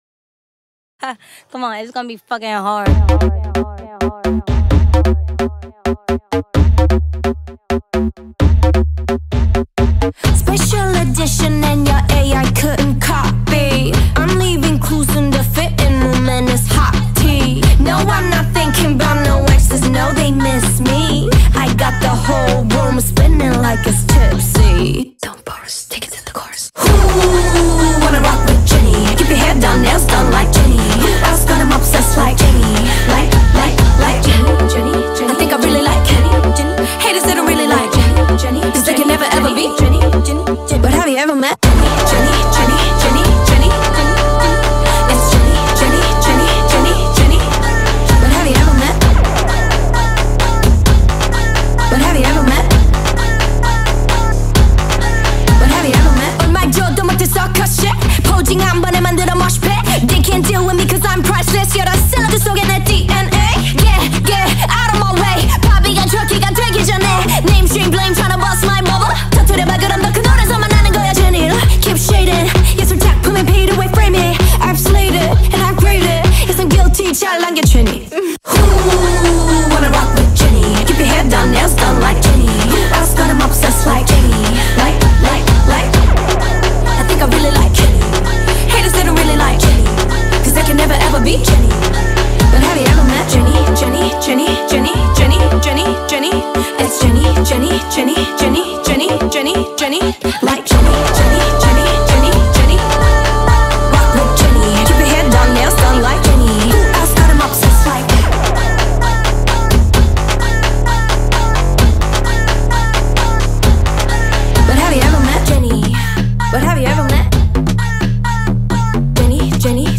این قطعه با فضایی مدرن و پرانرژی
ملودی جذاب و ضرب‌آهنگ کوبنده